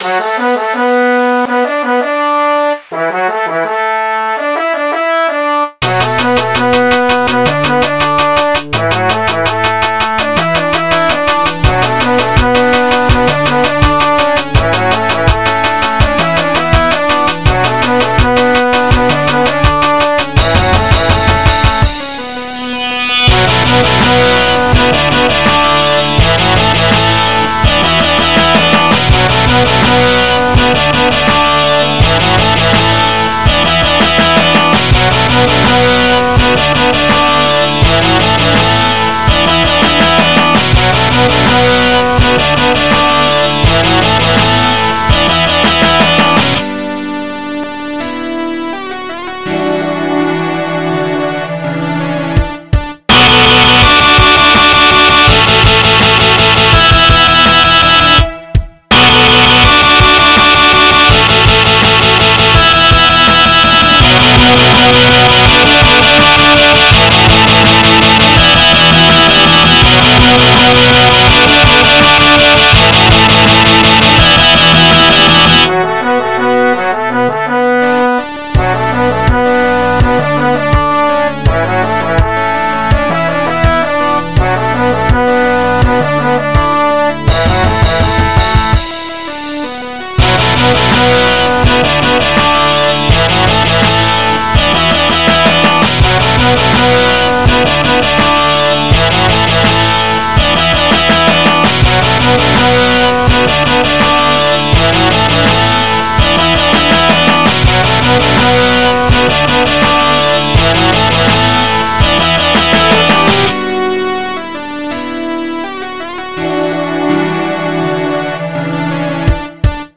MIDI 83.57 KB MP3